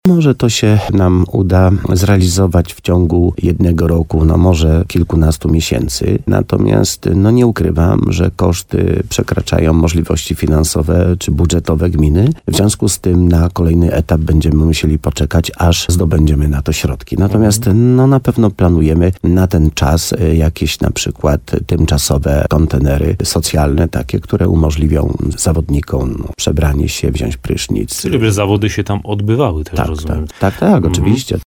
Jak przekonywał w programie Słowo za Słowo na antenie RDN Nowy Sącz wójt Leszek Skowron, pierwszy etap budowy powinien być dość szybko zrealizowany.